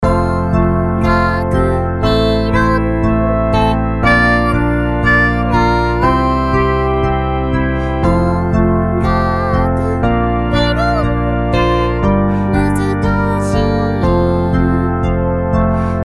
Ｃメジャースケールで作った後、Ｆメジャースケール（ヘ長調）にキーを移調しています。
最後に、ベースとコードと伴奏の旋律を別々の楽器で鳴らして重ねてみましょう。
ベースとコードと伴奏の旋律を別々の楽器で鳴らしてみた